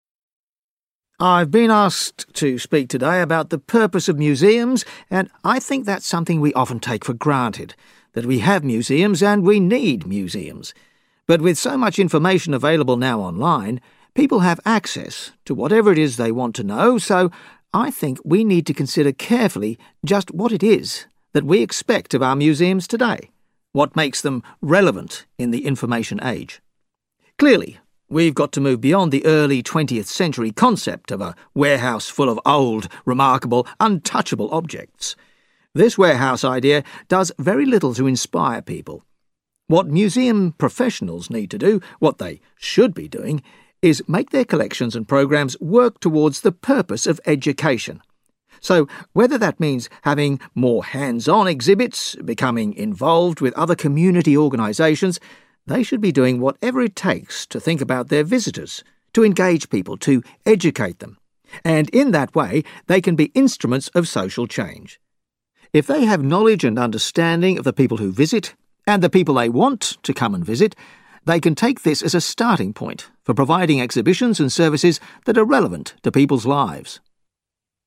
You will hear a lecture.